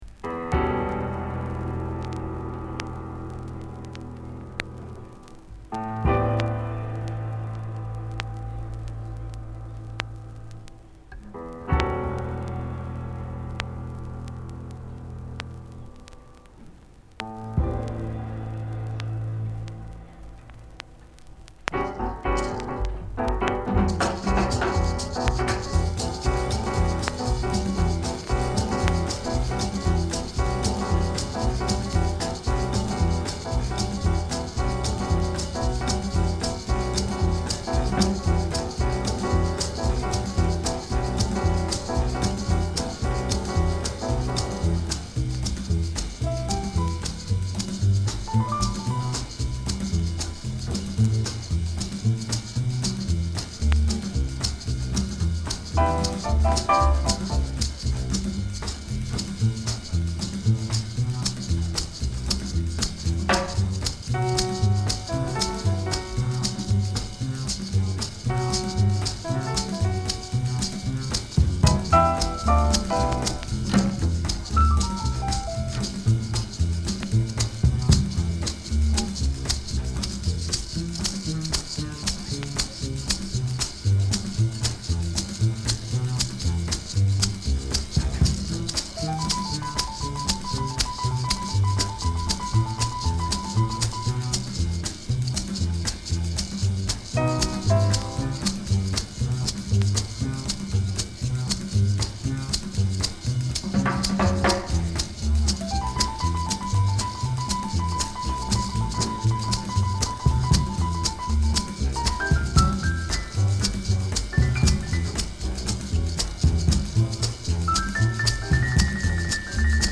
ワシントンDC録音のトリオ作品